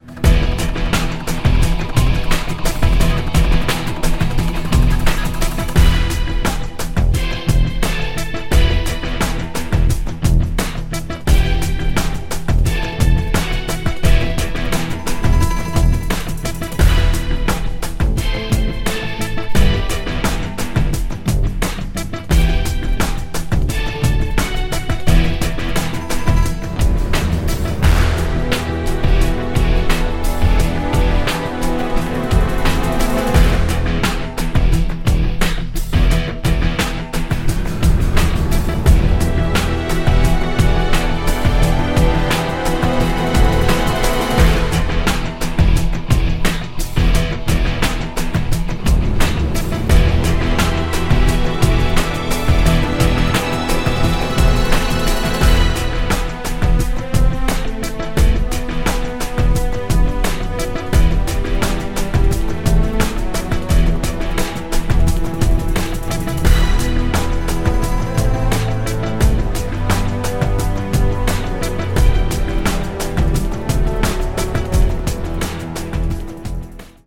a breathtaking behemoth of an action score